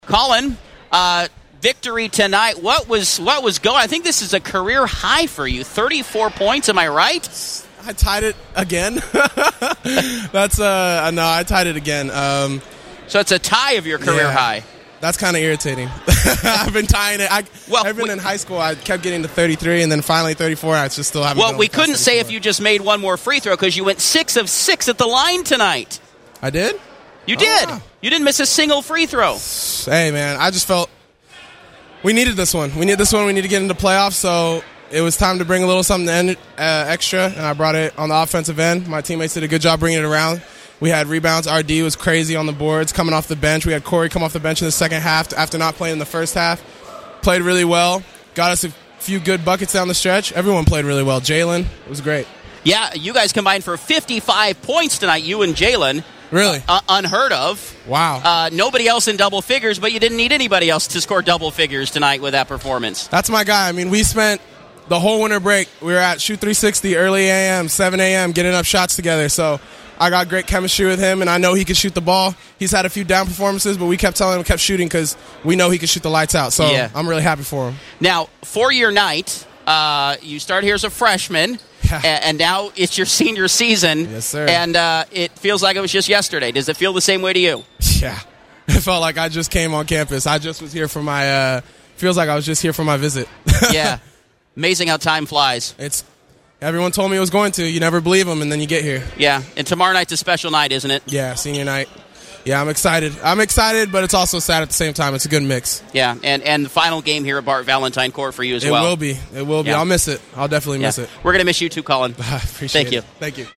Post Game Audio